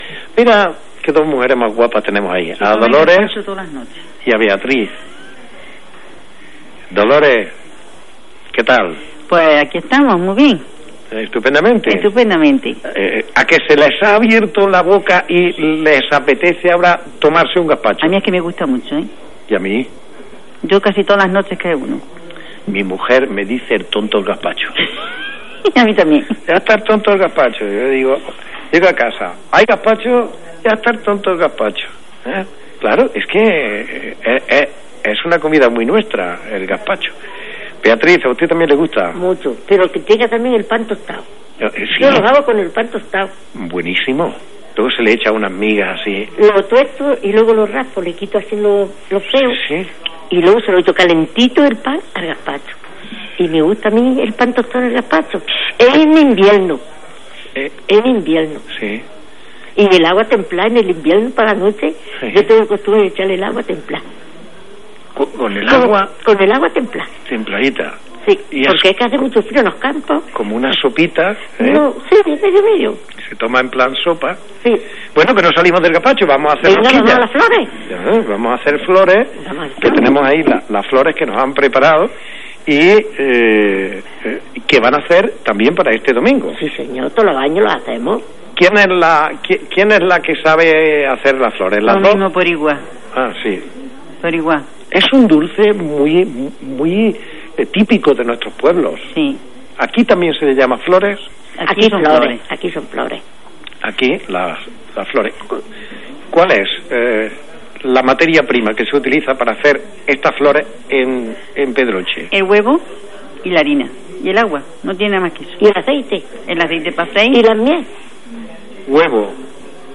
Entrevistas realizadas por RADIO LUNA SER, el 13 de abril, en un programa especial realizado desde Pedroche.